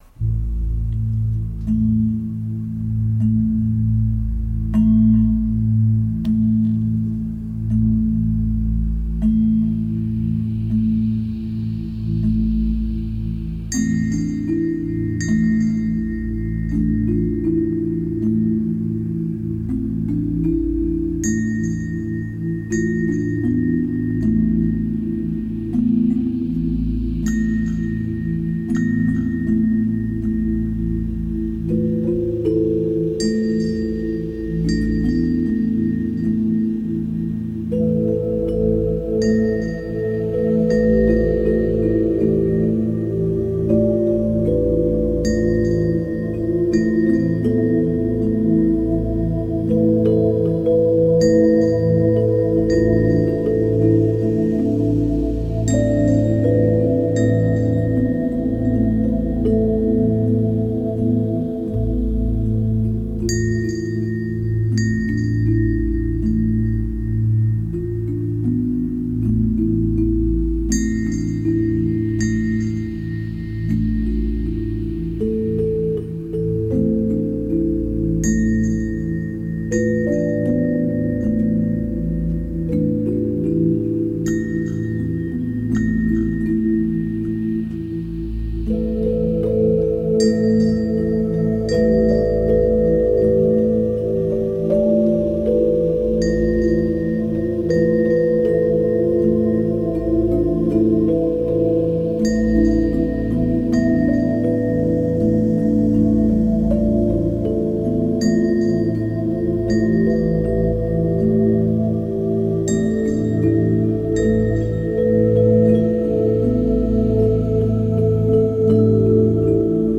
Ambient electronica.